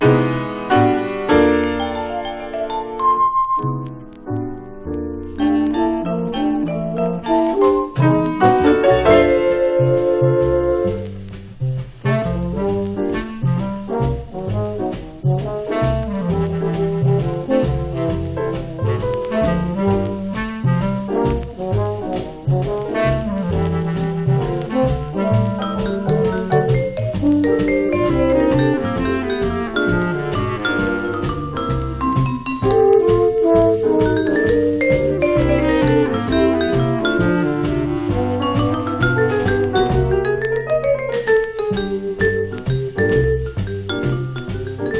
Jazz　USA　12inch　33rpm　Mono